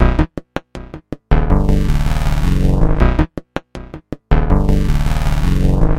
Synth 1 For Hardstyle 160BPM
描述：Synth/melody for Hardstyle 160BPM. Also for use at 150170BPM.
标签： 160 bpm Hardstyle Loops Synth Loops 1.01 MB wav Key : Unknown
声道立体声